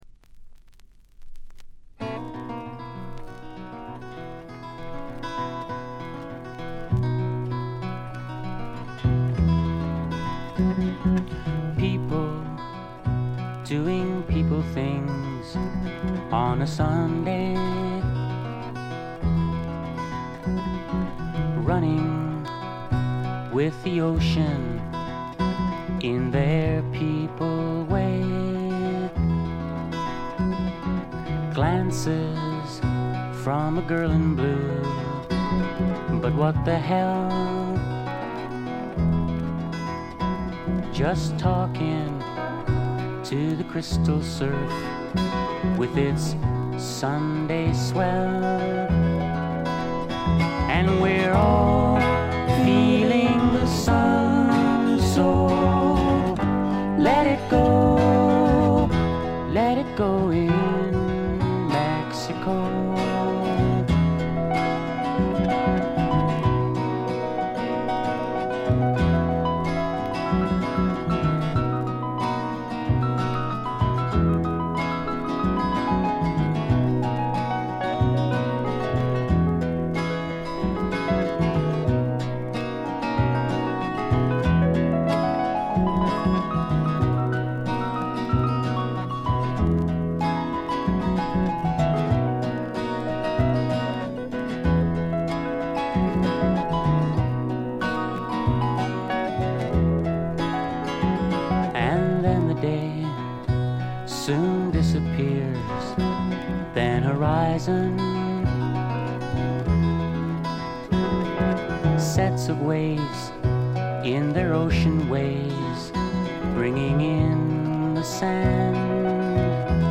部分試聴ですが軽微なバックグラウンドノイズ程度。
試聴曲は現品からの取り込み音源です。